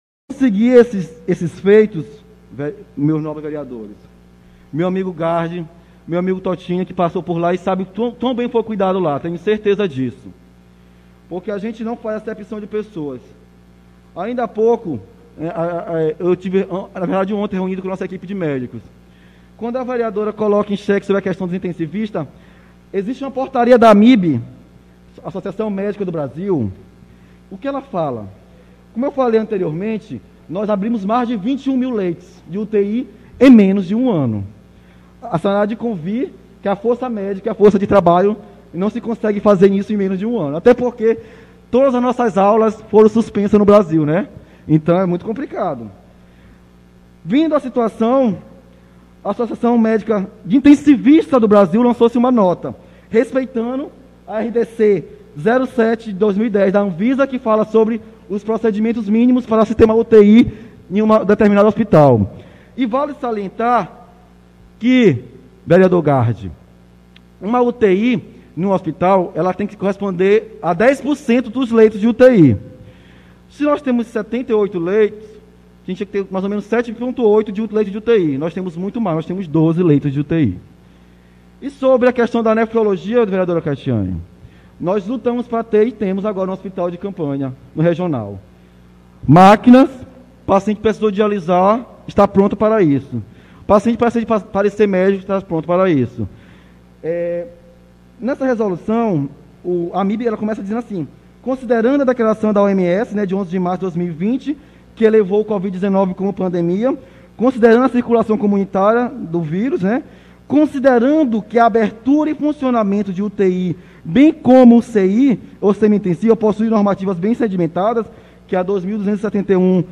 participou hoje (19), da sessão ordinária na Câmara de Vereadores